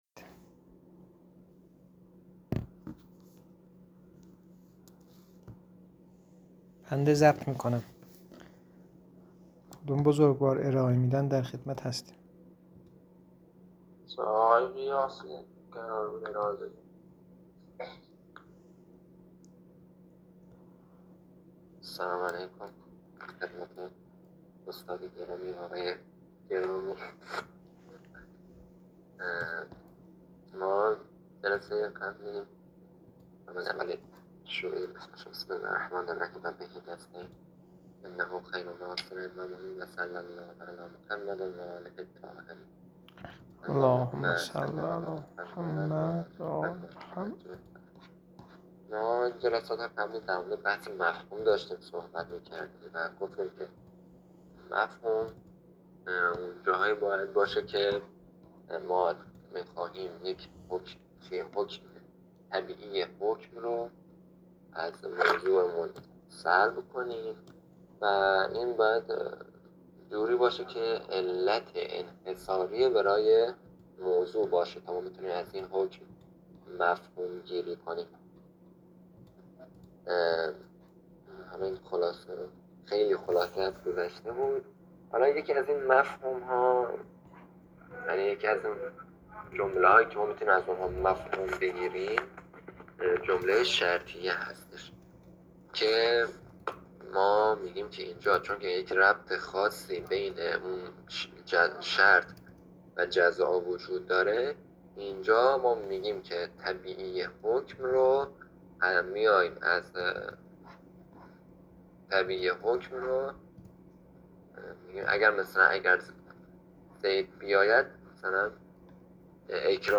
در این بخش، فایل های مربوط به تدریس كتاب حلقه ثانیه متعلق به شهید صدر رحمه الله